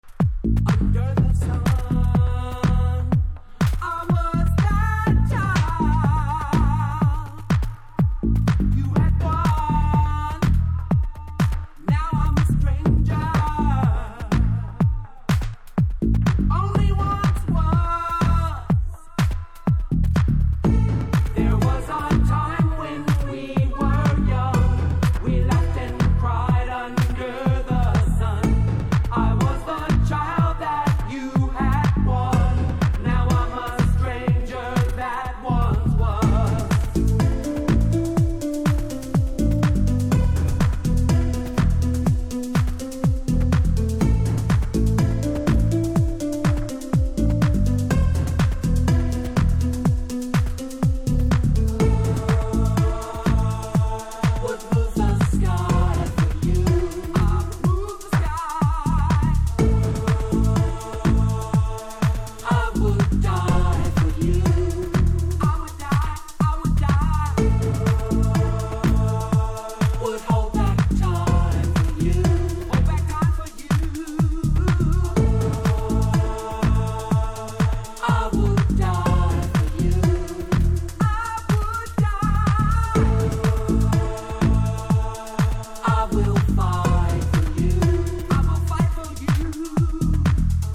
Re mastered for 2014
House